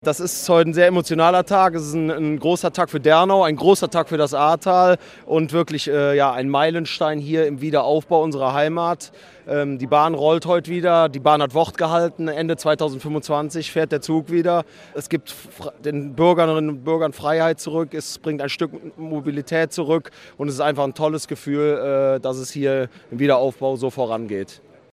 Ortsbürgermeister von Dernau, David Fuhrmann (FBL)
Kurz vor Fahrtantritt zeigte sich der Dernauer Ortsbürgermeister David Fuhrmann (FBL) emotional bewegt im SWR-Interview: "Das ist ein Meilenstein im Wiederaufbau unserer Heimat."